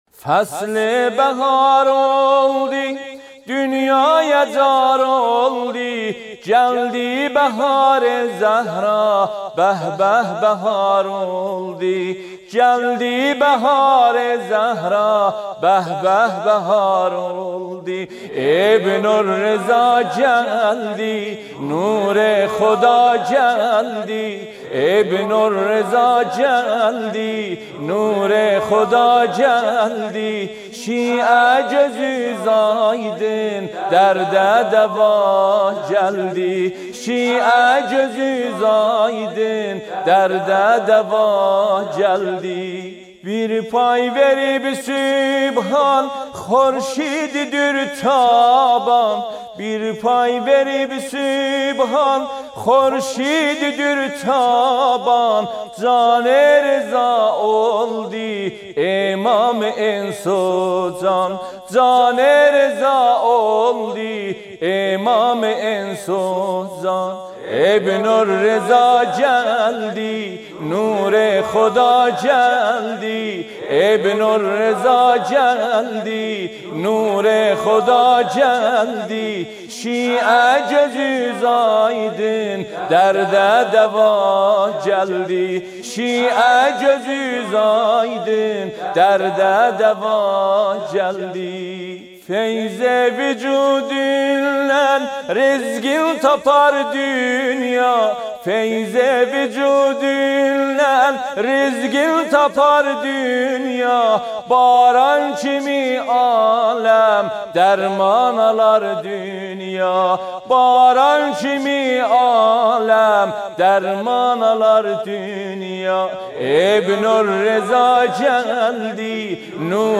مولودی آذری مولودی ترکی